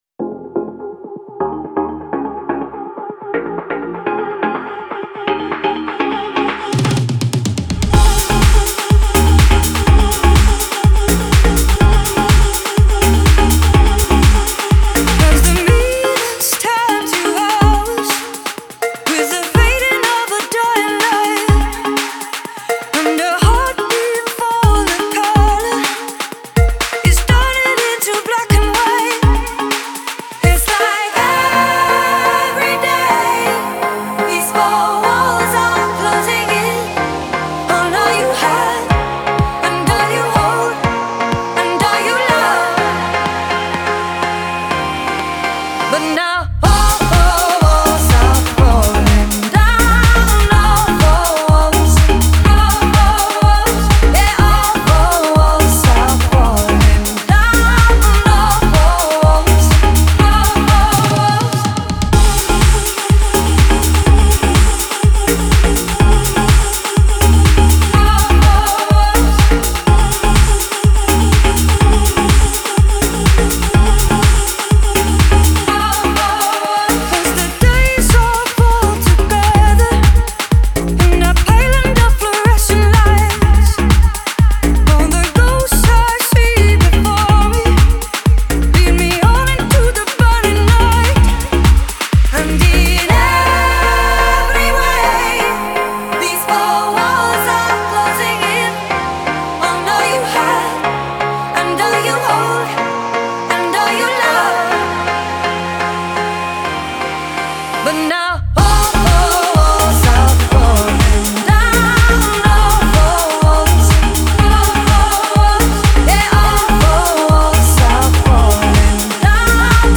Genre : Dance, Electronics